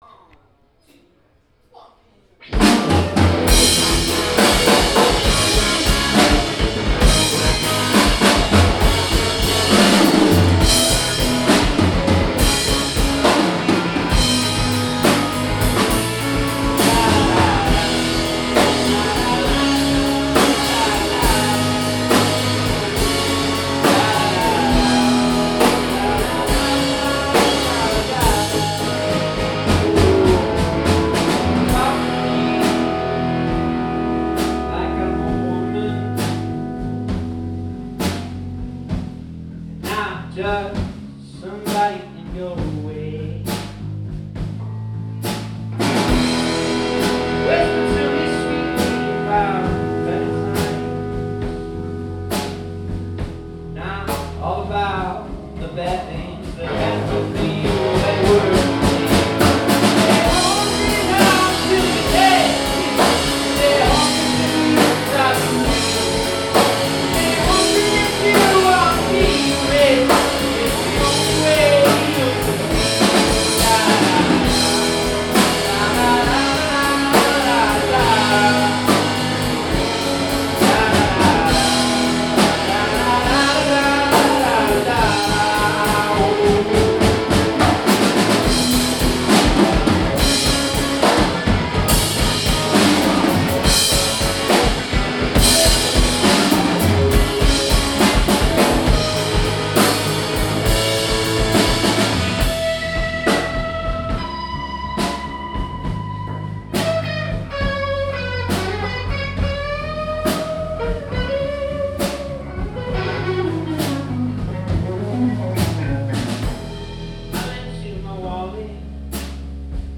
Alternative Rock Live at Mohawk Place